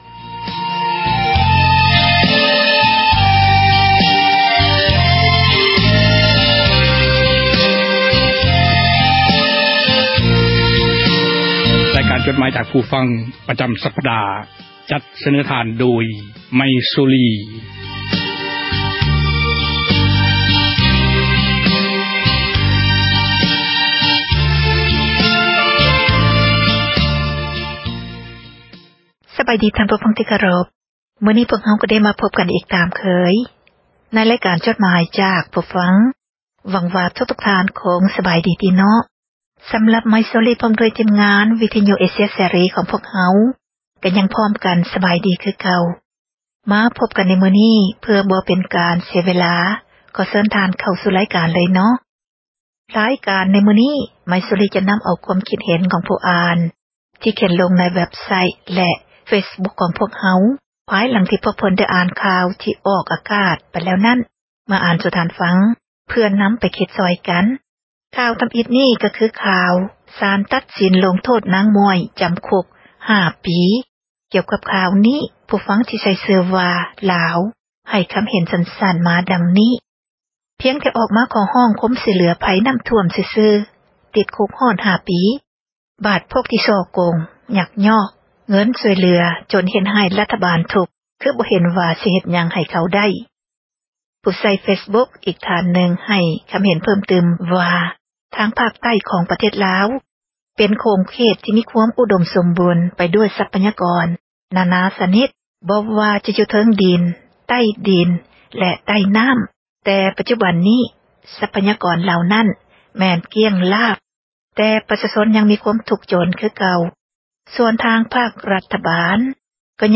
ໝາຍເຫດ: ຄວາມຄິດເຫັນ ຂອງທ່ານຜູ່ອ່ານ ທີ່ສະແດງອອກ ໃນເວັບໄຊທ໌ ແລະ ເຟສບຸກຄ໌ ຂອງວິທຍຸ ເອເຊັຽ ເສຣີ ນັ້ນ, ພວກເຮົາ ທິມງານ ວິທຍຸ ເອເຊັຽ ເສຣີ ໃຫ້ຄວາມສຳຄັນ ແລະ ຂອບໃຈ ນຳທຸກໆຖ້ອຍຄຳ, ແລະ ມີໜ້າທີ່ ນຳມາອ່ານໃຫ້ທ່ານ ໄດ້ຮັບຟັງກັນ ແລະ ບໍ່ໄດ້ເສກສັນປັ້ນແຕ່ງໃດໆ, ມີພຽງແຕ່ ປ່ຽນຄຳສັພ ທີ່ບໍ່ສຸພາບ ໃຫ້ເບົາລົງ ເທົ່ານັ້ນ. ດັ່ງນັ້ນ ຂໍໃຫ້ທ່ານຜູ່ຟັງ ຈົ່ງຕັດສິນໃຈເອົາເອງ ວ່າ ຄວາມຄຶດຄວາມເຫັນນັ້ນ ເປັນໜ້າເຊື່ອຖື ແລະ ຄວາມຈິງ ຫຼາຍໜ້ອຍປານໃດ.